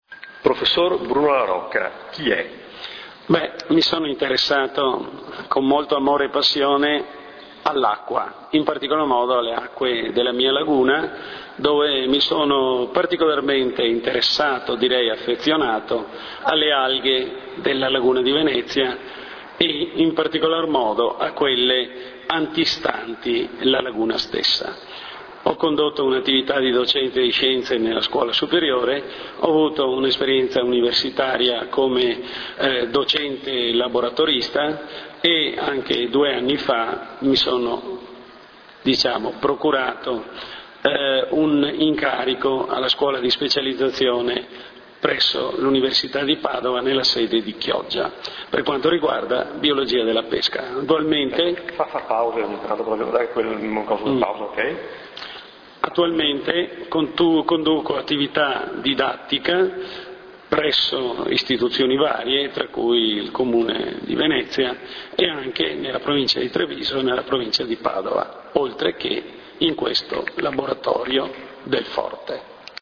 L'intervista           [... attiva l'audio, file .mp3]